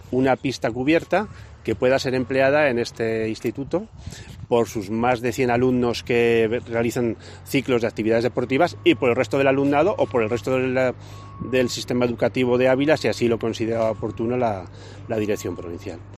Director general de Centros e Infraestructuras, José Miguel Sáez Carnicer